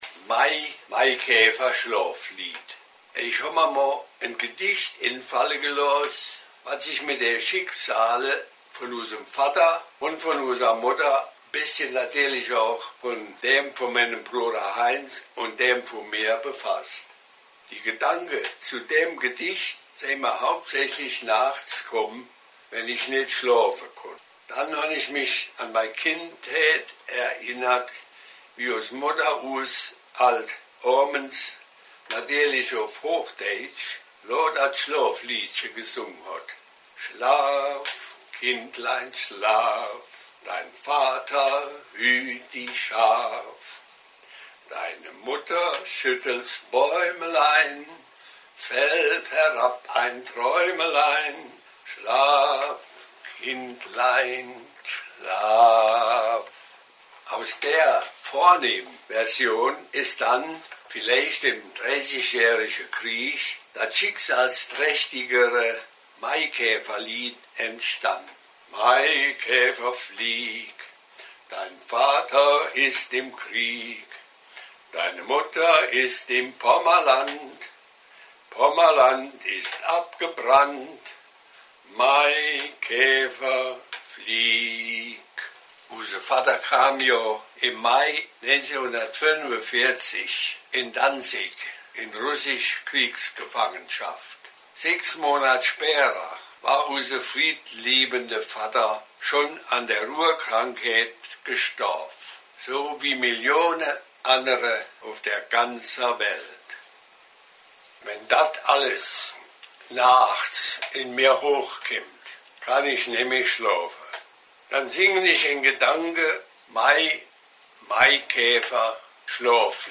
Irmenach Tonaufnahmen von selbst gefertigter DVD